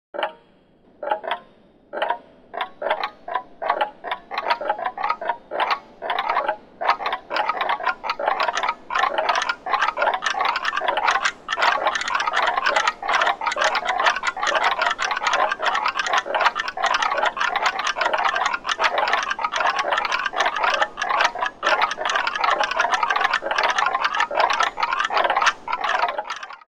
Spooky-ticking-ambiance-sound-effect.mp3